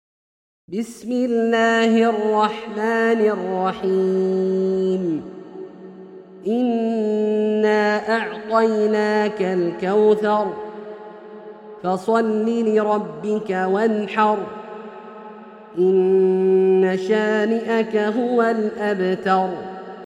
سورة الكوثر - برواية الدوري عن أبي عمرو البصري > مصحف برواية الدوري عن أبي عمرو البصري > المصحف - تلاوات عبدالله الجهني